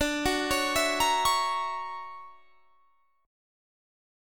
D+M9 chord